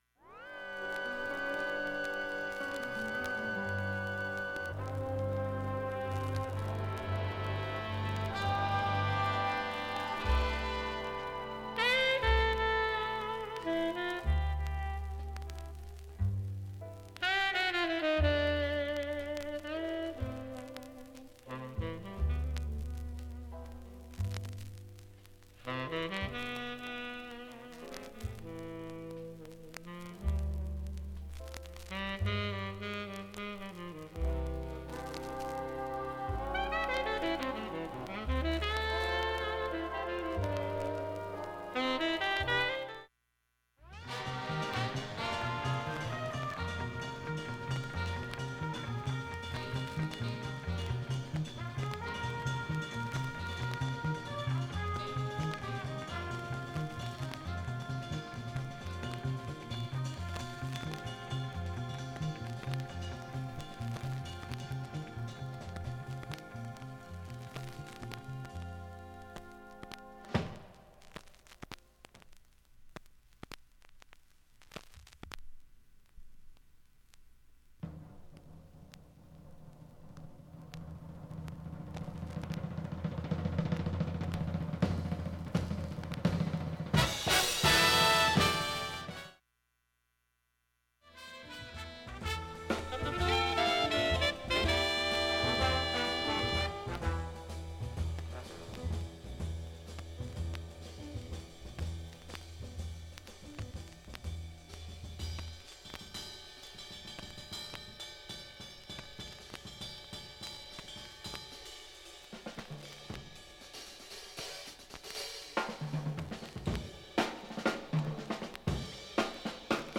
普通に聴けます音質良好全曲試聴済み。
プツプツになりますがかすかです。
単発のかすかなプツが８箇所
グルーヴィなビッグバンド作品。